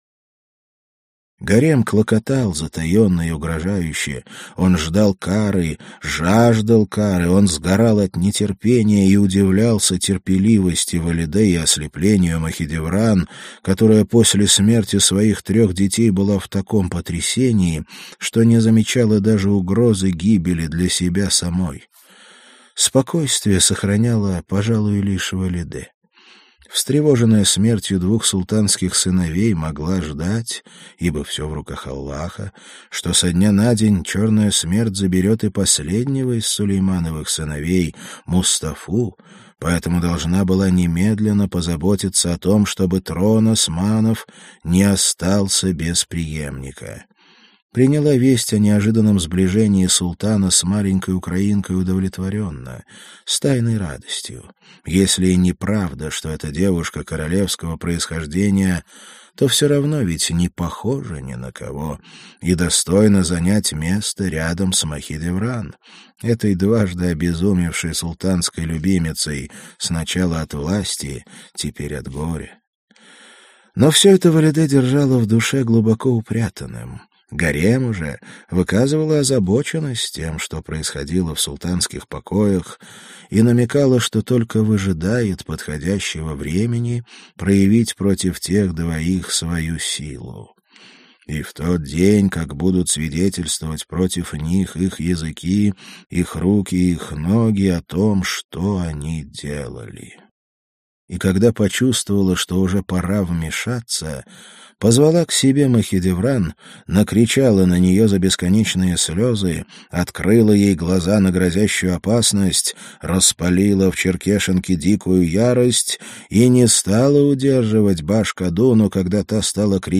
Аудиокнига Роксолана. В гареме Сулеймана Великолепного | Библиотека аудиокниг